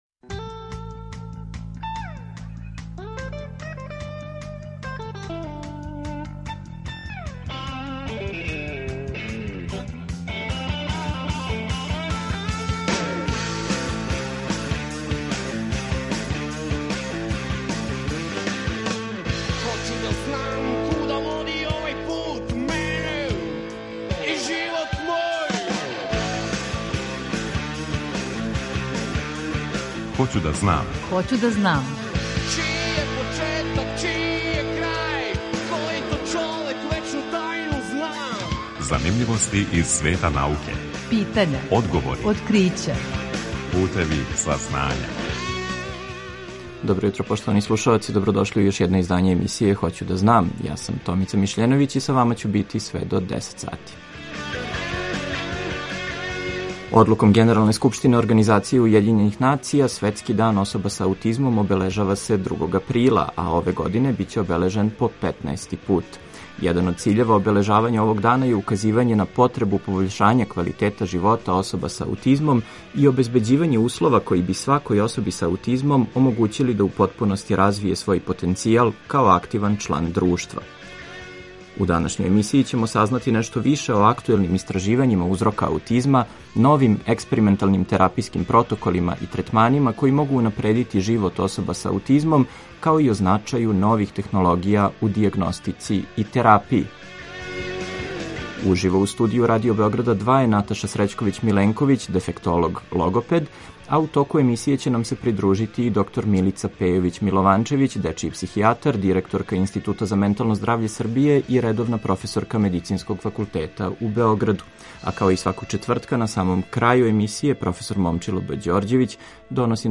Светски дан особа са аутизмом | Радио Београд 2 | РТС